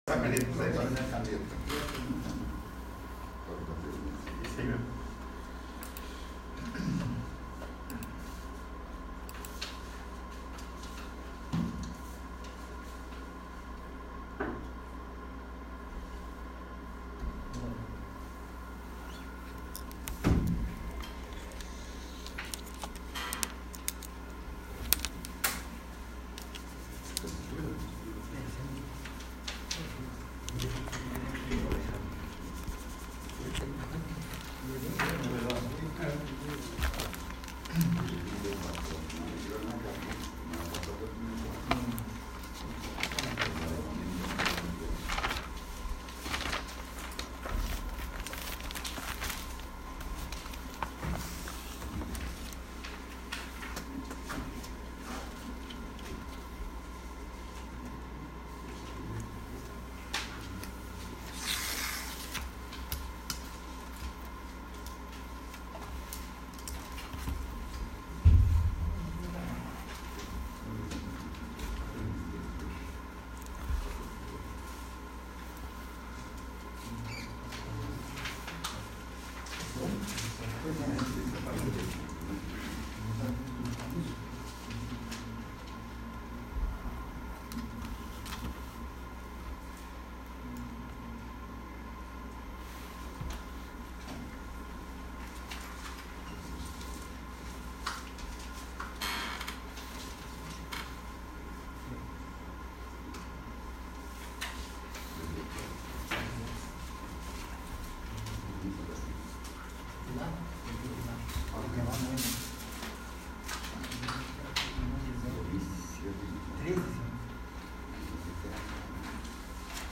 14º. Sessão Ordinária
14o-sessao-ordinaria